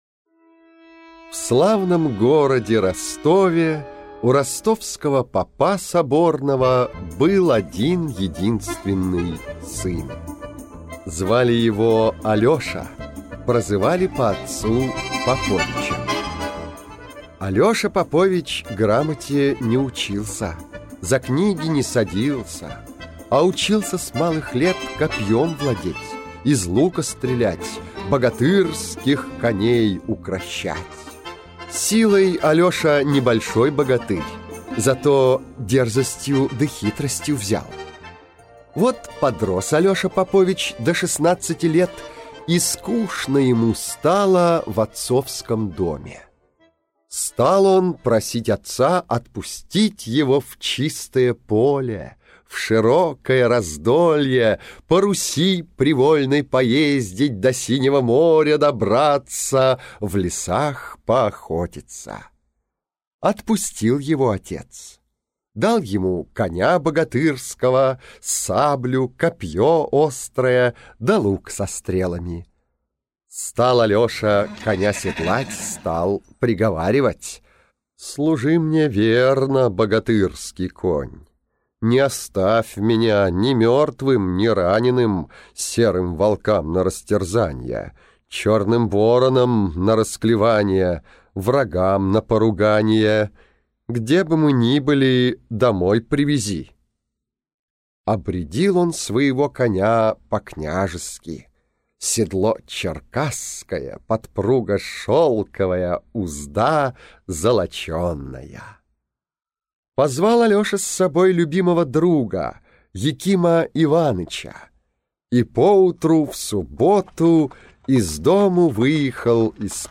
Слушать Алеша Попович и Тугарин Змеевич - русская народная аудиосказка.